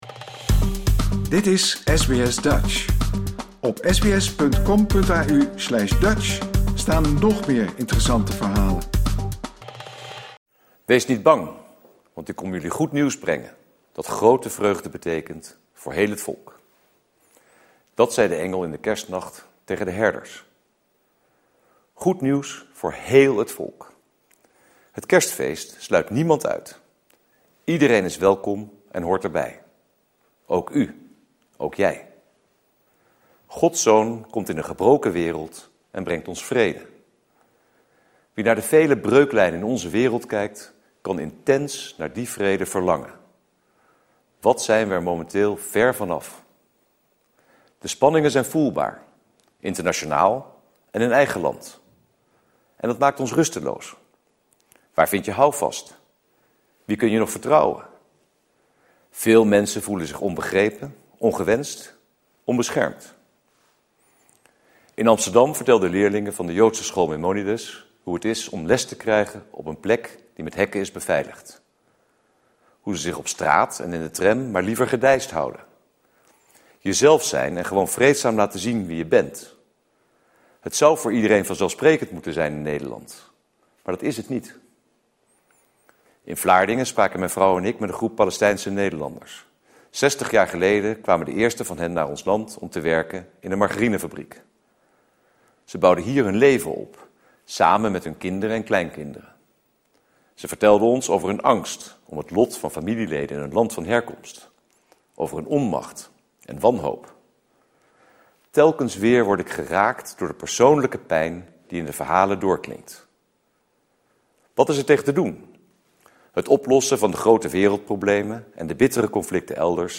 Gisterenmiddag om 15:00 uur Nederlandse tijd werd traditiegetrouw de kersttoespraak van Koning Willem-Alexander uitgezonden door de NOS. De koning stond stil bij huidige spanningen in de samenleving en riep op tot weerbaarheid. Ook benadrukte hij dat in Nederland iedereen gelijk is en discriminatie bij wet verboden.